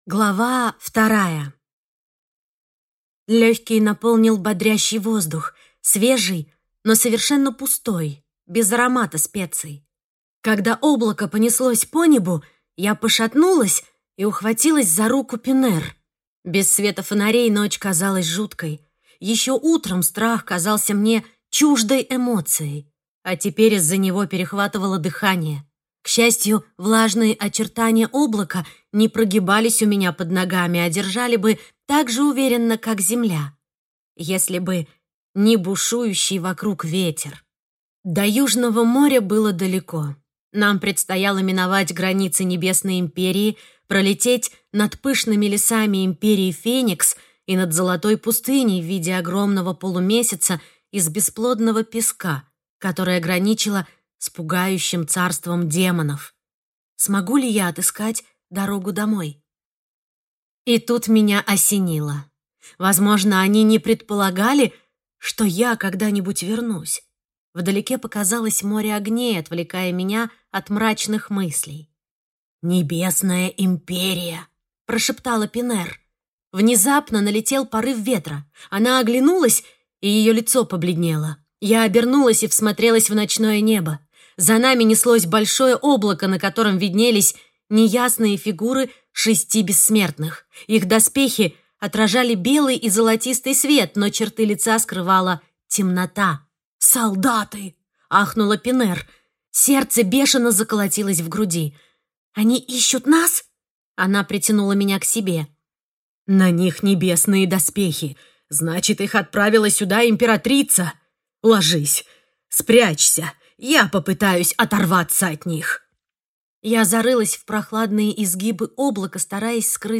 Аудиокнига Дочь Лунной богини | Библиотека аудиокниг